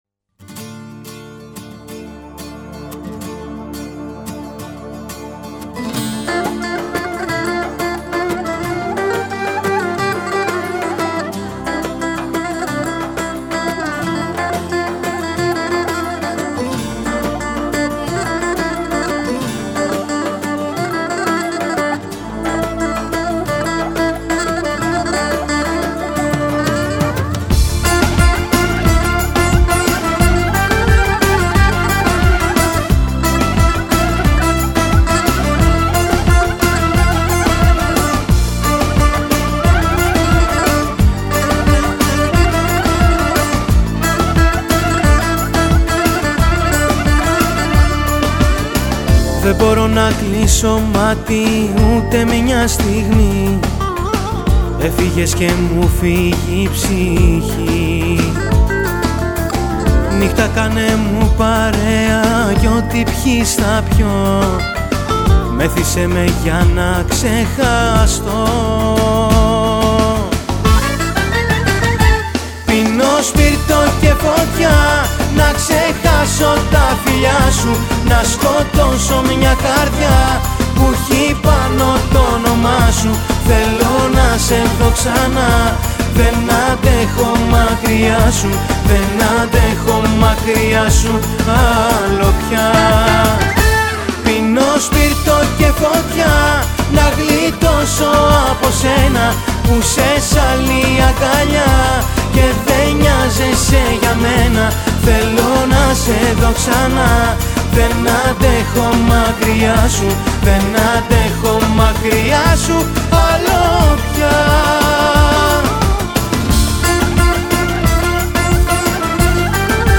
ترانه یونانی ελληνικό τραγούδι